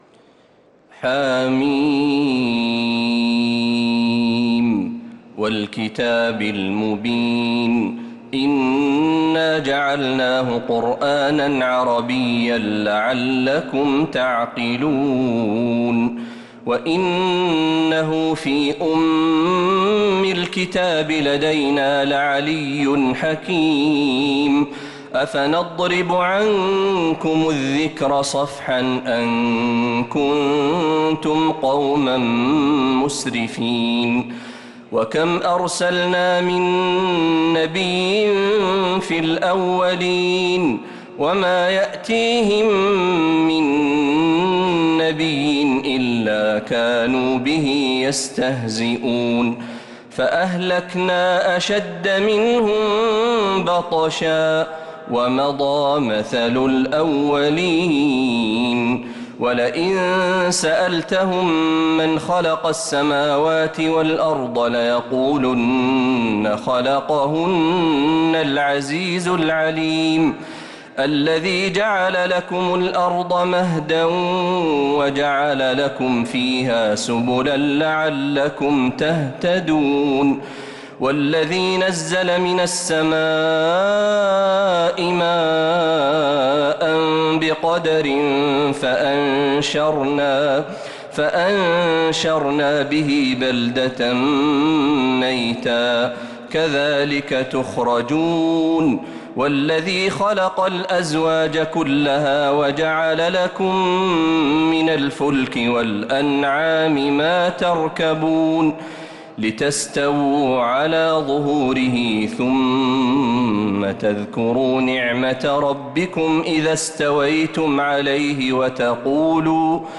سورة الزخرف كاملة من تهجد الحرم النبوي للشيخ محمد برهجي | رمضان 1445هـ > السور المكتملة للشيخ محمد برهجي من الحرم النبوي 🕌 > السور المكتملة 🕌 > المزيد - تلاوات الحرمين